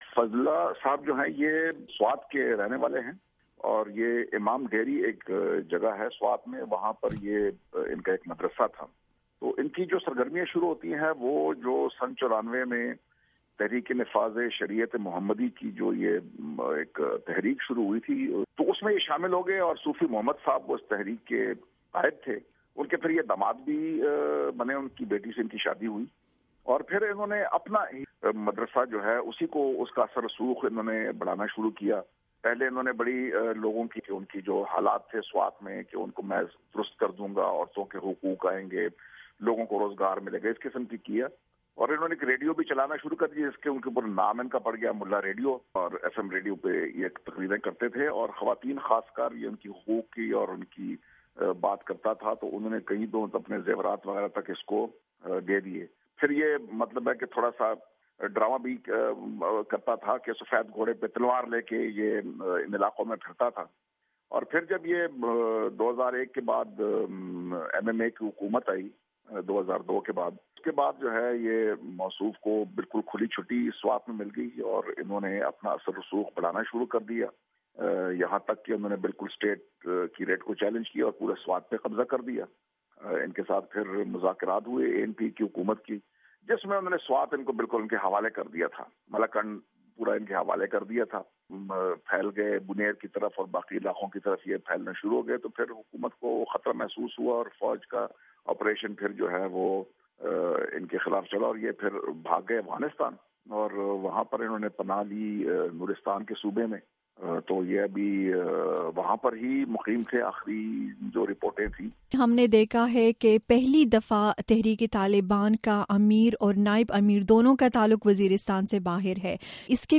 گفتگو